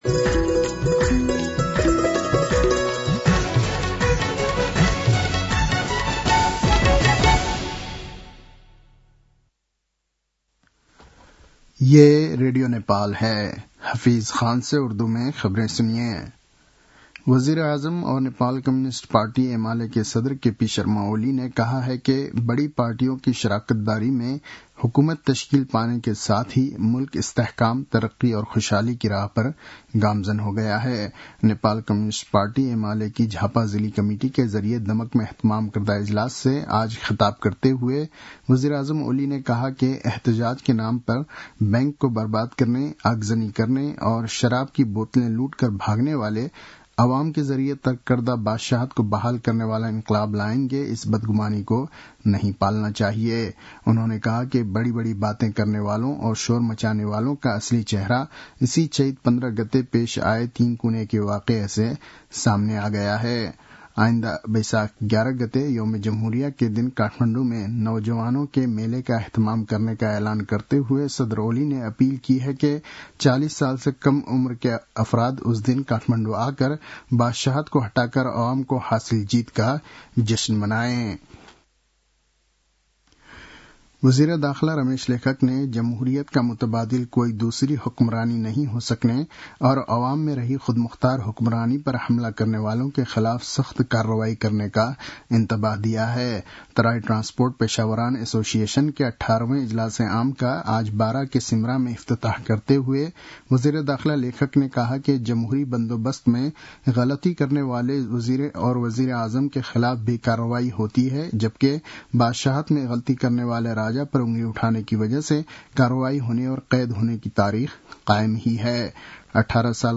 उर्दु भाषामा समाचार : ३० चैत , २०८१